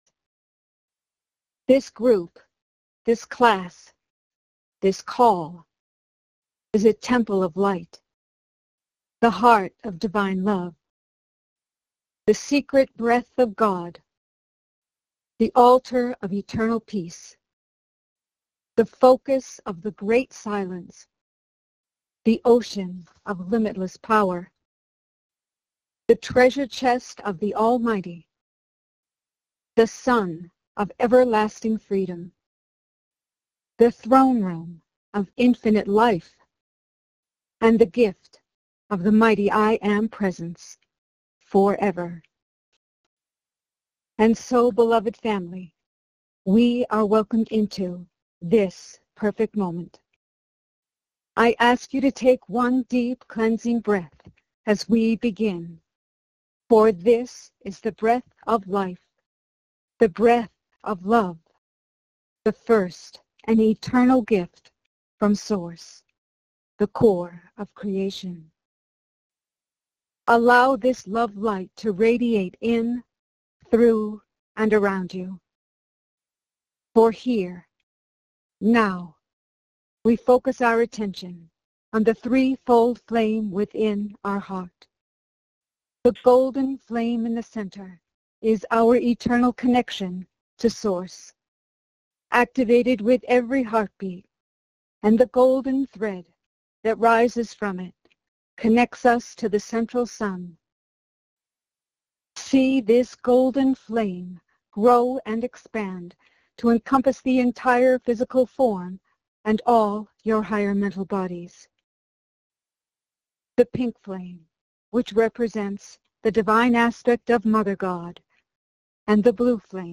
Join in group meditation with Lord Sananda.